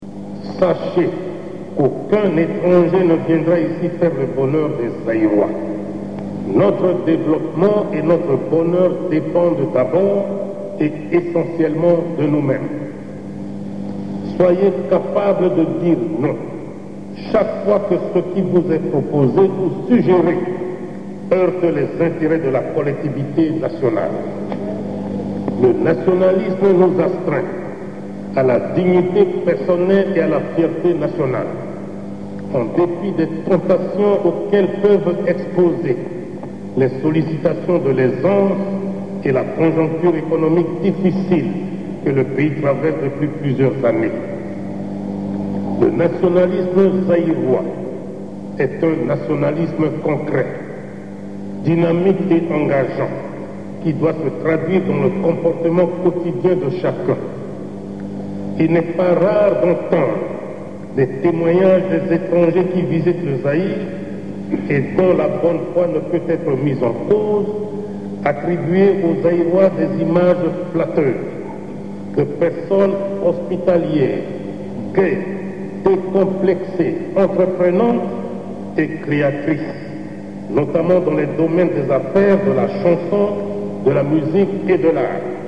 Extraits du discours de Mobutu en 1988 à l'Université de Kisangani
kisangani1988_nationalisme.mp3